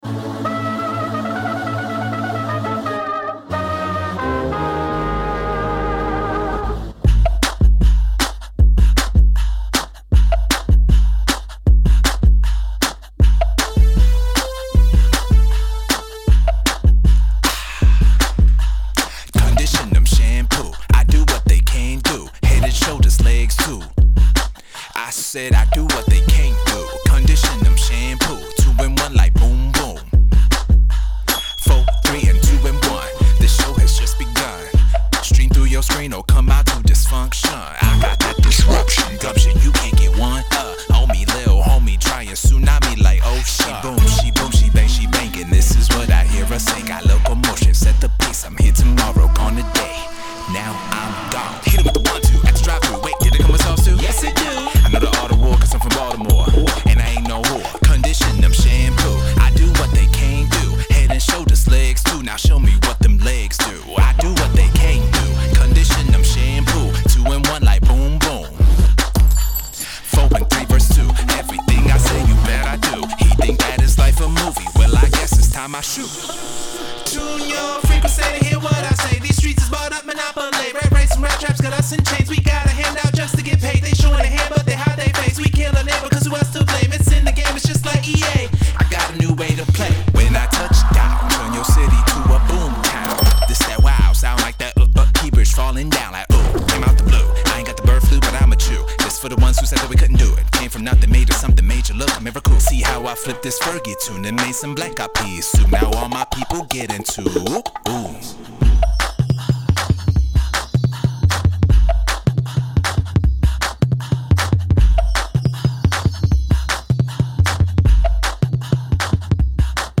braggadocios-style rap
The Super Cool Awesome Music Studio in Tallahassee, Florida.